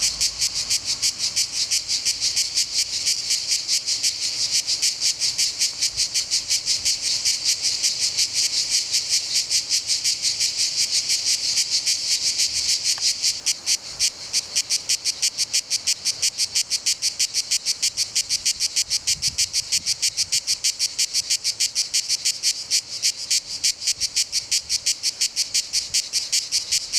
Lied der Cigale
Ihr Gesang erfüllt die heißen Tage und erreicht in der Mittagshitze seinen ekstatischen Höhepunkt mit beachtlicher Lautstärke.
Man nennt das von den Zikadenmännchen erzeugte Geräusch "Gesang", aber im Grunde genommen ist es ein sirrender Laut, der von einem besonderen Körperorgan erzeugt wird.
Cigalle.wav